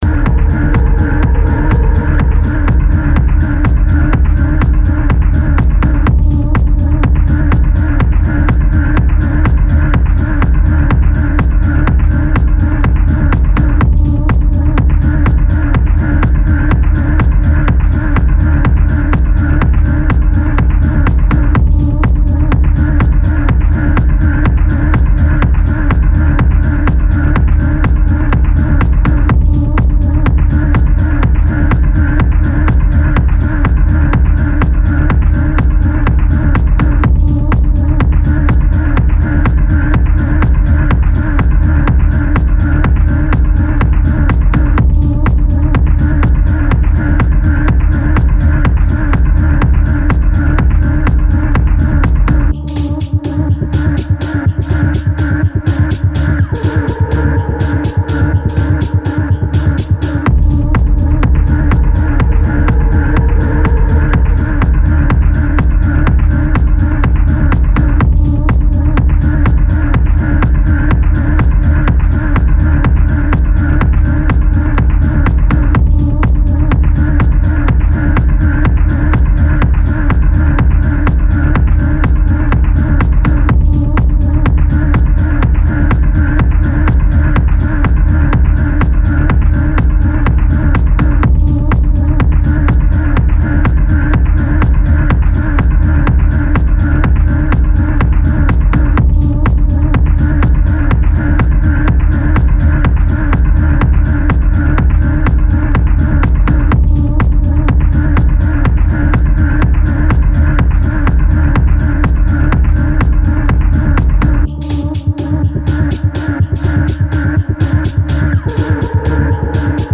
stomping through the slush on dark city streets